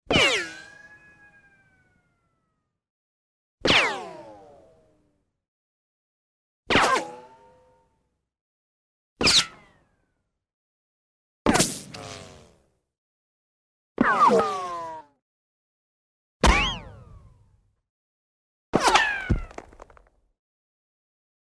серия звуков рикошета пуль для монтажа